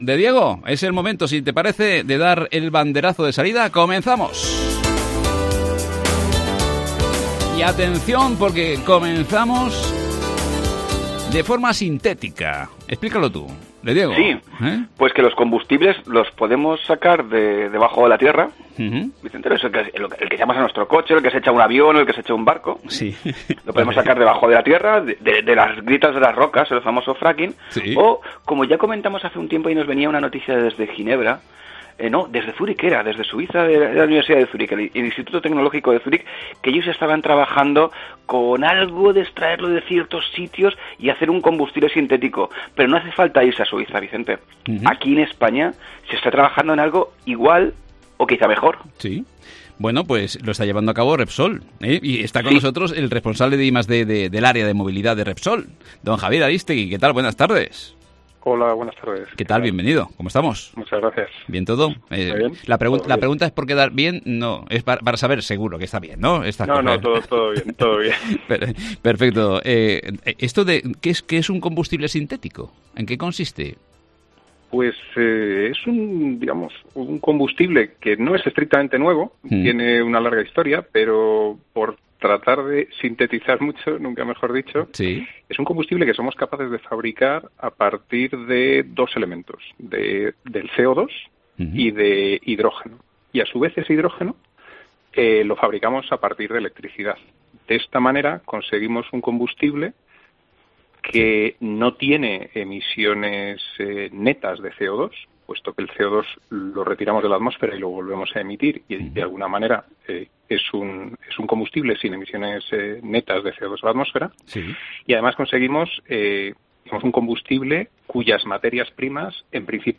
Charlamos en Luz de Cruce